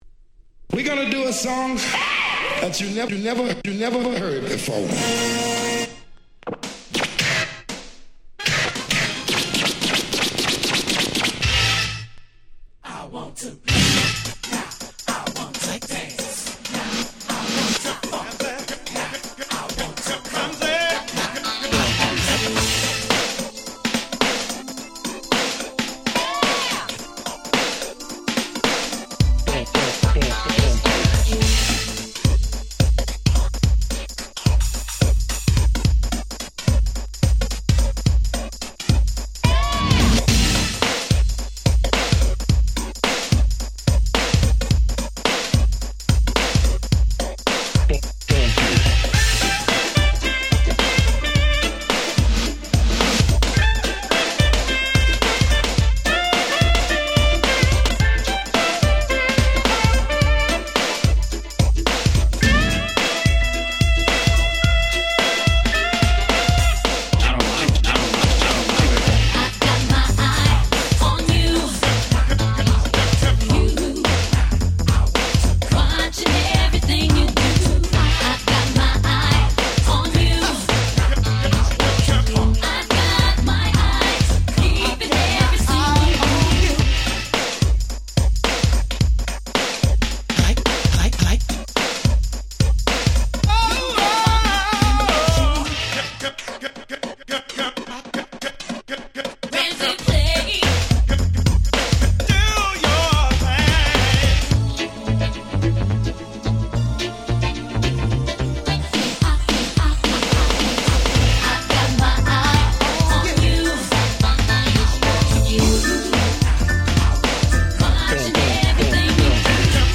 89' Very Nice New Jack Swing !!
彼の美しいピアノの音色、FunkyなSaxに女性Vocal、、、100点満点！！
NJS ニュージャックスウィング 90's ハネ系 R&B